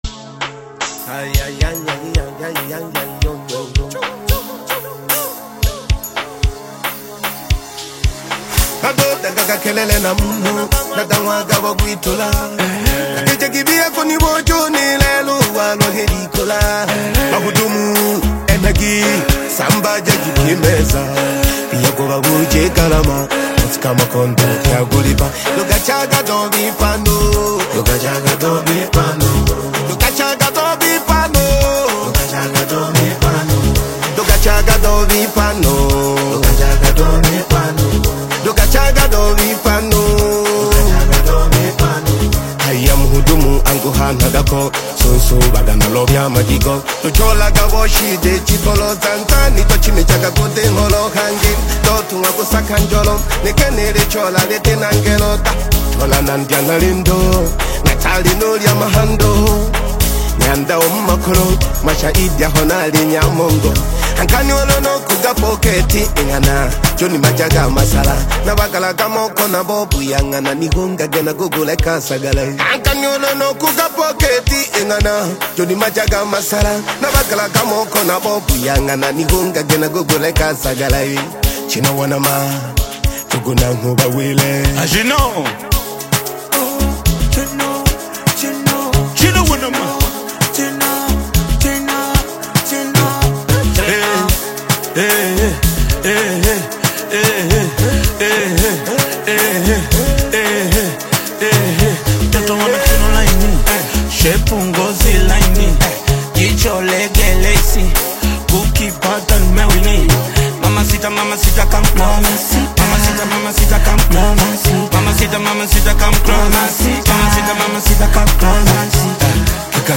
house single
Afro-house